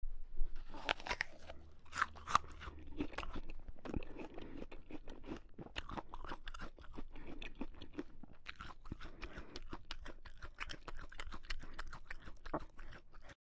拍手
描述：一个人拍手的快速掌声。
Tag: 手拍 快速 掌声 OWI